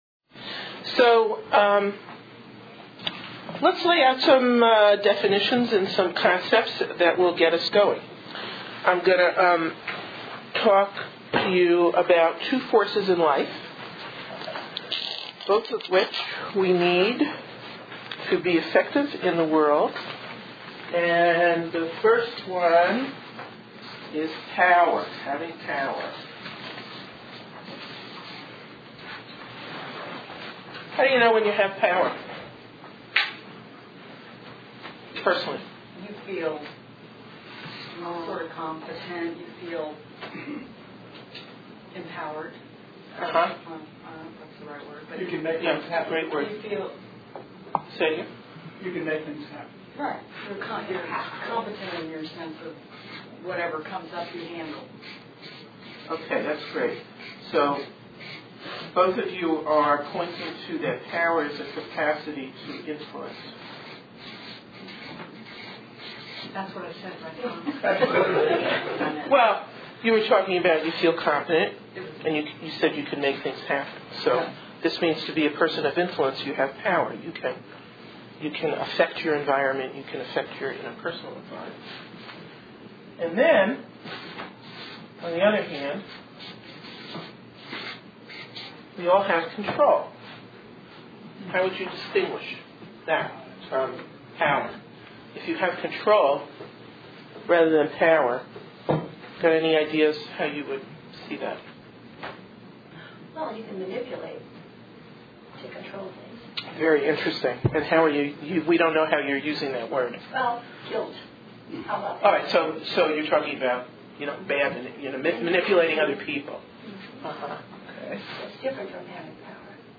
Presentation on Victim Perpetrators